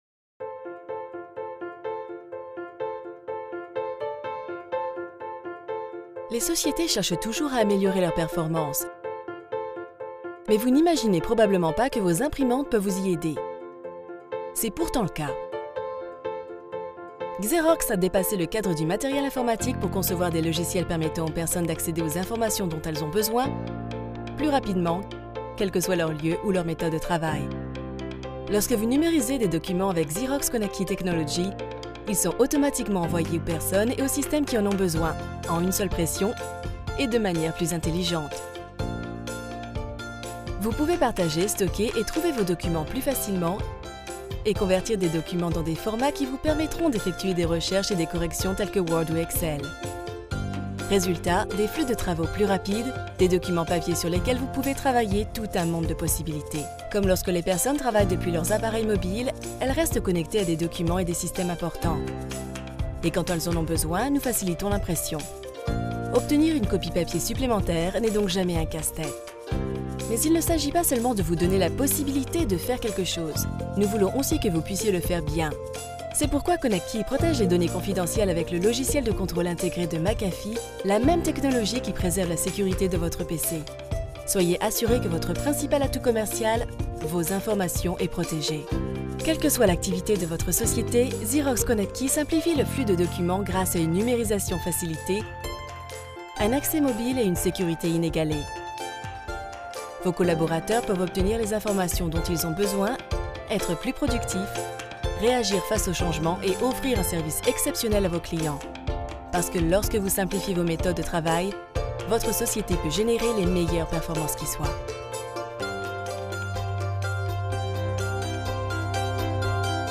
Native French Voice Over artist.
Professional home studio, based in New York. 15+ years experience.
Sprechprobe: Werbung (Muttersprache):